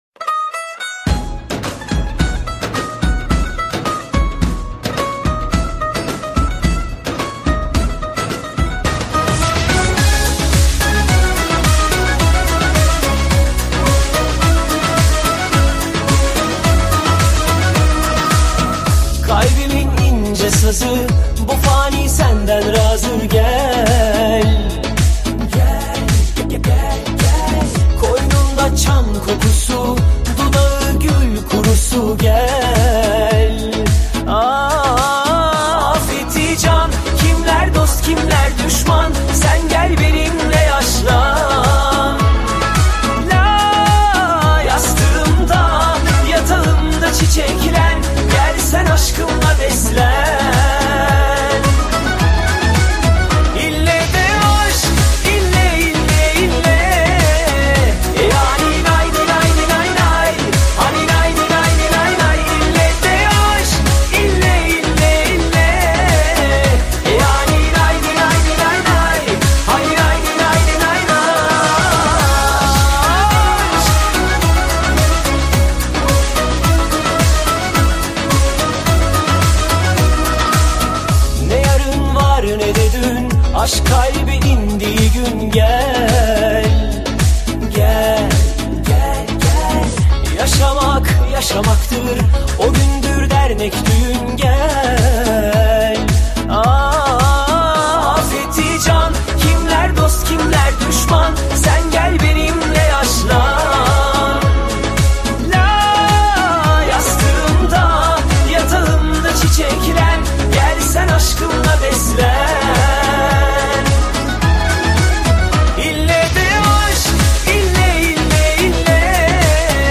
ترکی شاد